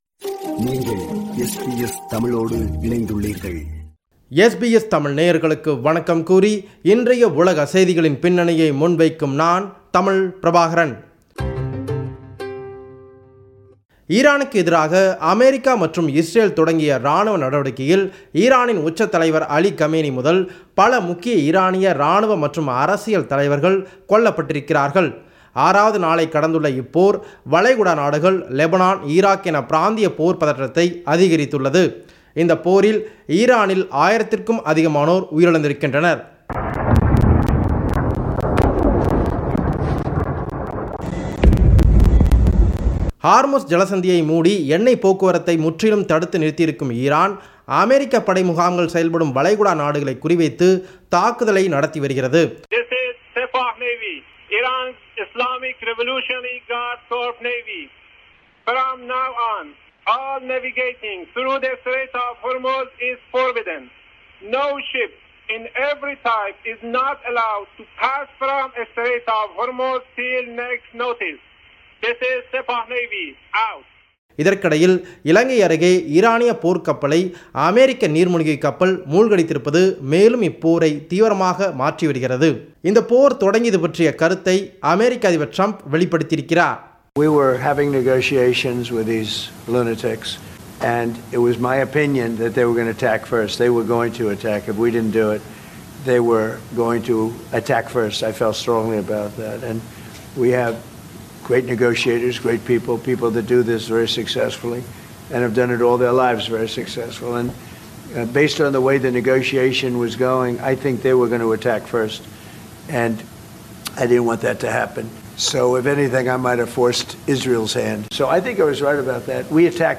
உலகம்: இந்த வார செய்திகளின் தொகுப்பு